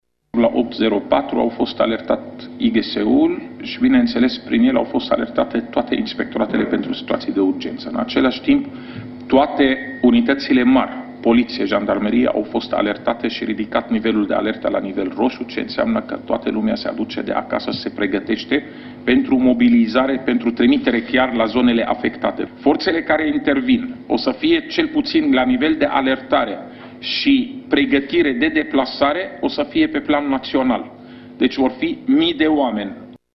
Secretarul de stat în MAI Raed Arafat a precizat că în acest exerciţiu sunt implicate toate structurile şi autorităţile responsabile într-o astfel de situaţie, nu doar forţele MAI: